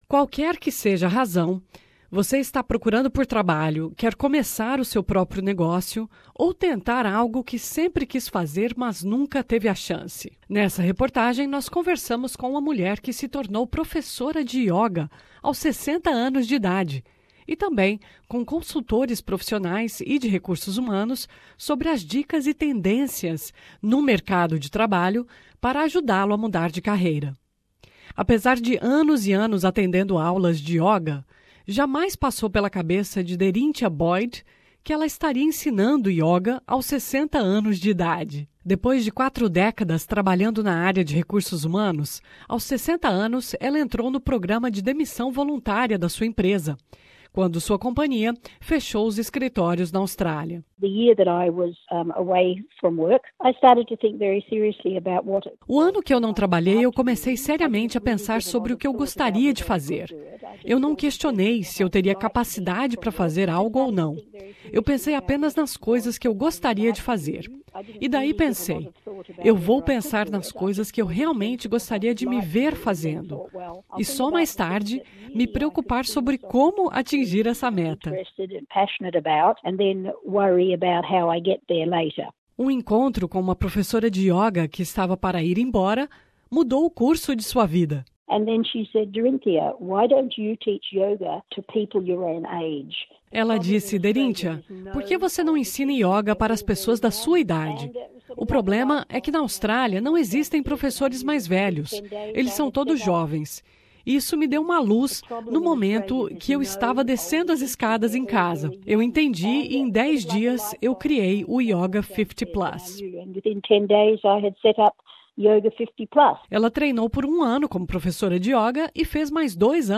Qualquer que seja a razão: você está procurando por trabalho, quer começar o seu próprio negócio, ou tentar algo que sempre quis fazer mas nunca teve a chance. Nessa reportagem nós conversamos com uma mulher que se tornou professora de yoga aos 60 anos, e também com consultores profissionais e de recursos humanos sobre as dicas e tendências no mercado de trabalho para ajudá-lo a mudar de carreira.